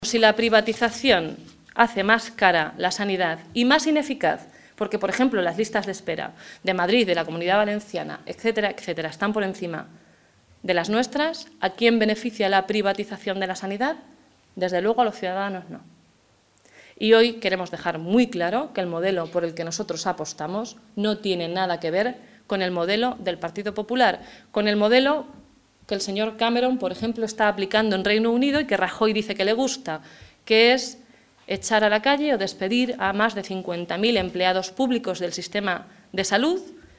Cortes de audio de la rueda de prensa